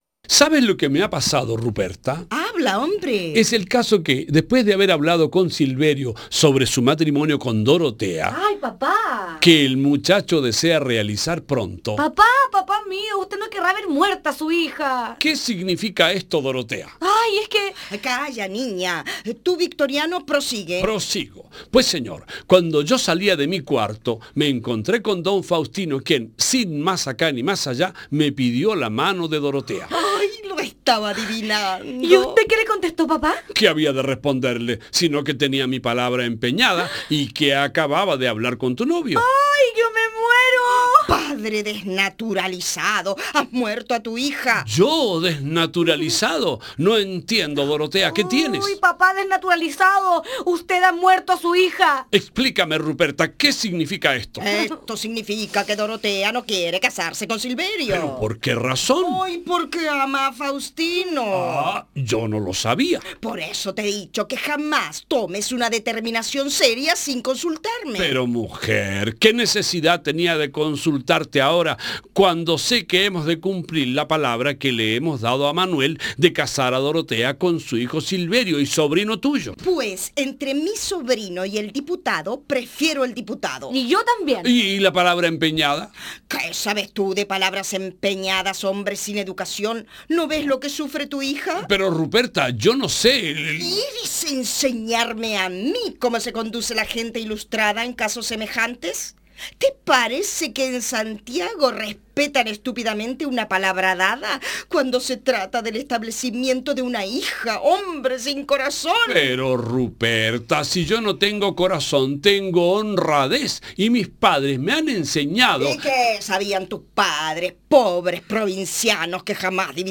Como en Santiago – Lecturas dramatizadas